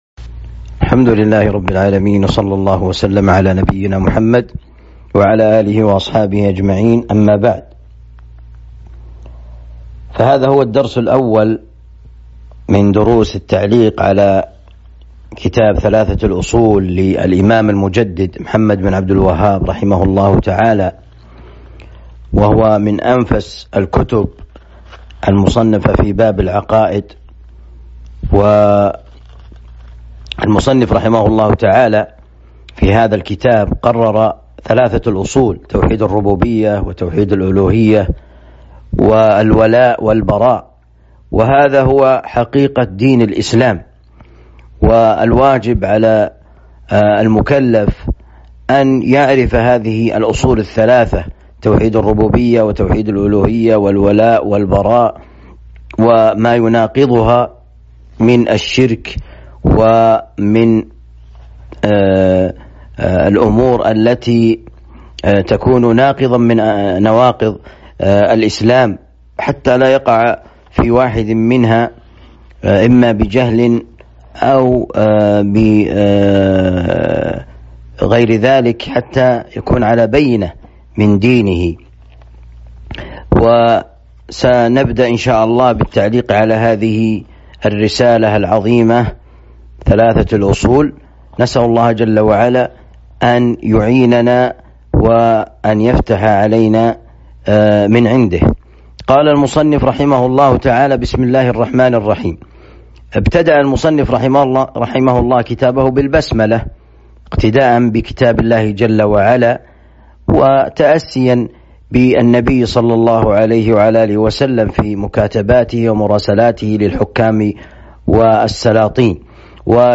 الدرس الأول من شرح ثلاثة الأصول